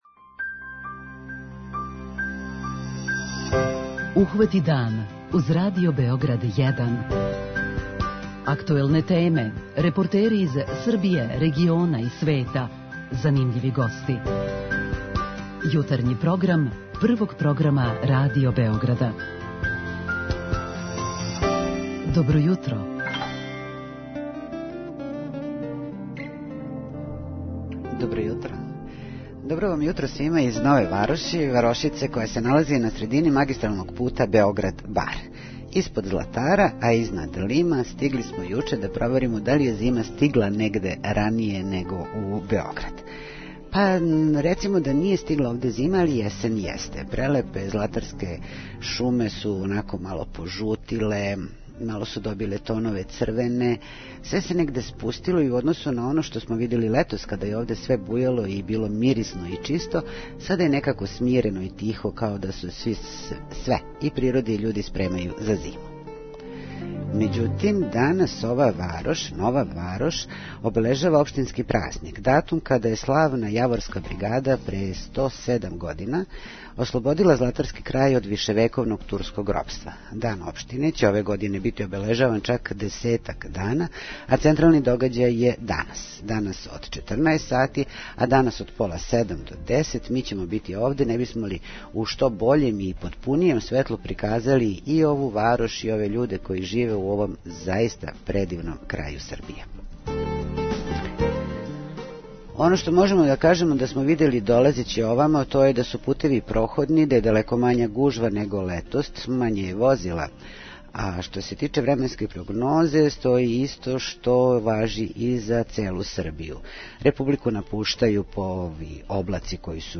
Ухвати дан - уживо из Нове Вароши
преузми : 37.82 MB Ухвати дан Autor: Група аутора Јутарњи програм Радио Београда 1!